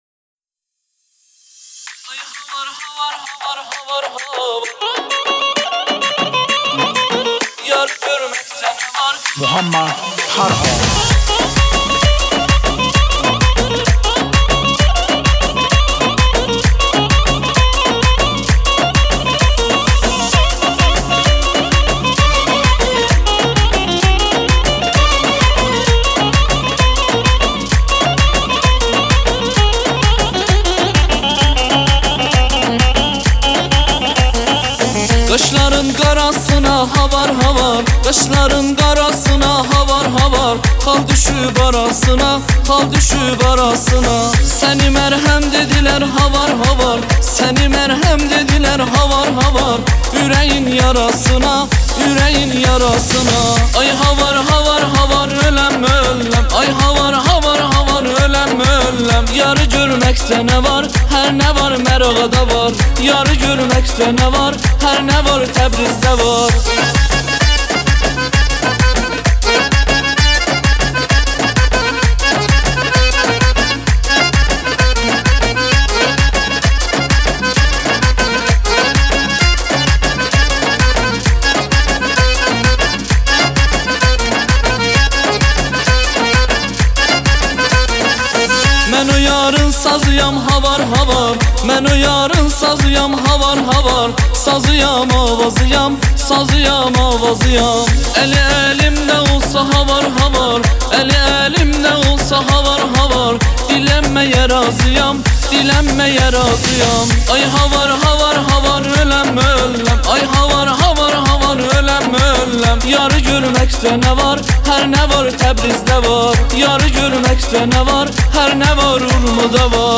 آهنگ ترکی